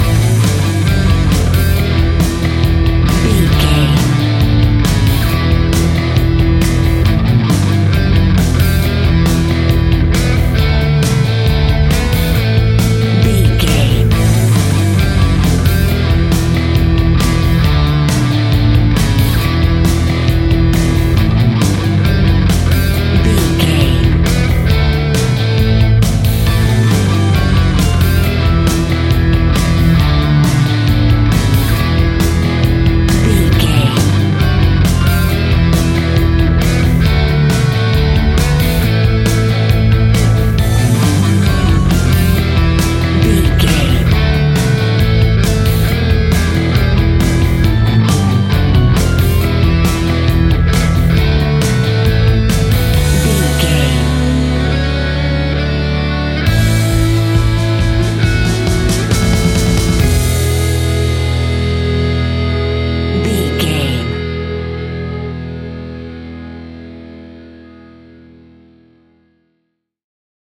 Epic / Action
Fast paced
Ionian/Major
hard rock
distortion
Rock Bass
Rock Drums
heavy drums
distorted guitars
hammond organ